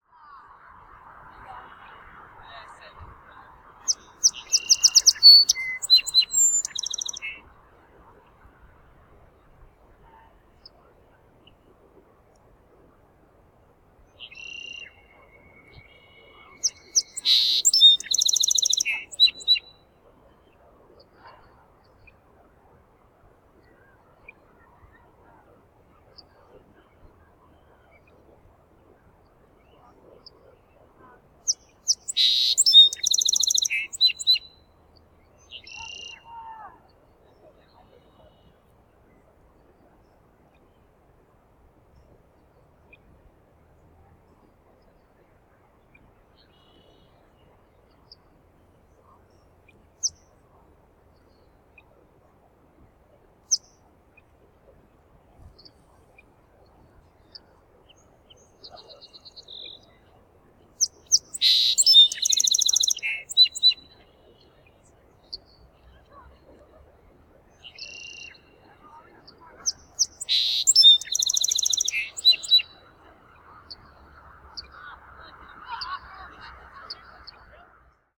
Spring trip to Yosemite Valley
Bird song
025_birds_cars_curry_meadow.ogg